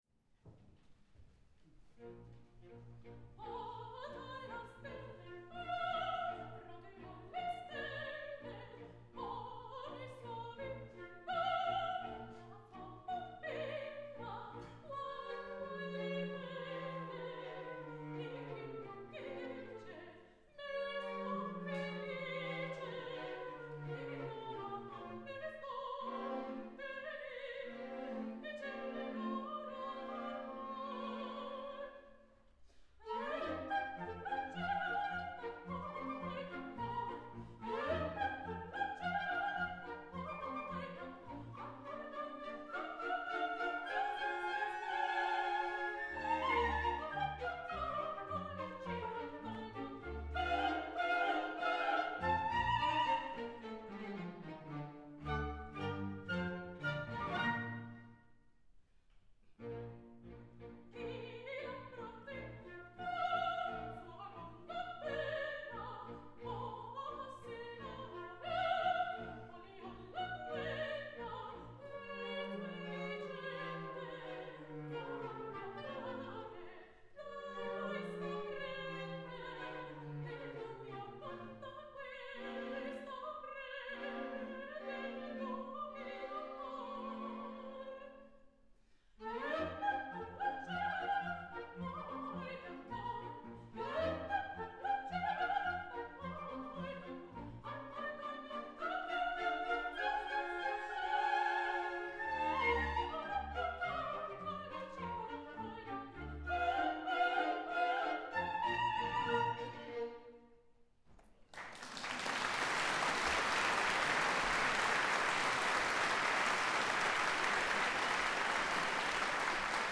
En aria, kan det va nåt?
Inspelningen ger en tydlig livekänsla (vilket jag gillar med denna typ av produktioner) det låter inte studio om den.
Men det var något som saknades i klangen - det lät en aning komprimerat rent klangmässigt.
Förmdodligen är det lokalen samt mikrofonplaceringarna som man hör.
Dessutom "peak-level" ligger i applåden i slutet.
Denna inspelning gjordes med rumsmickar och jag ska testa i fyrkanal och se vad det ger.